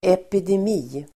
Uttal: [epidem'i:]